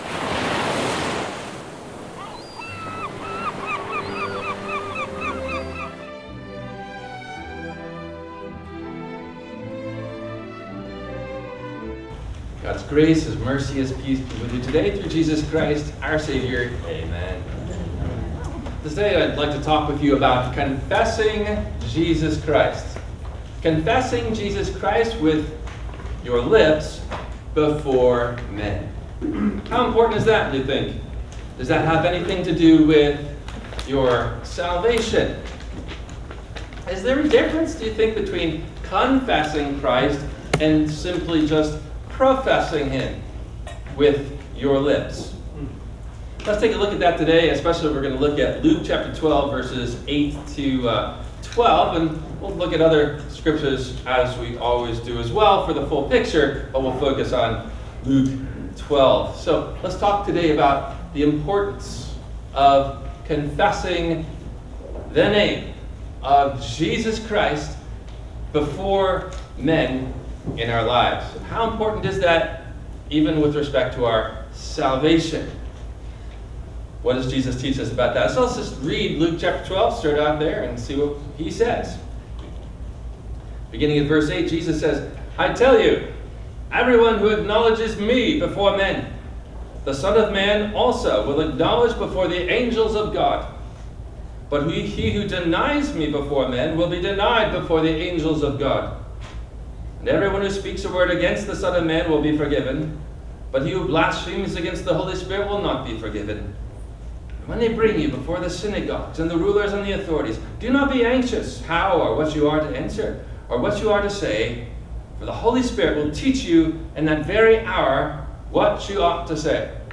How Important is it … to confess the name of Jesus Christ? – WMIE Radio Sermon – August 18 2025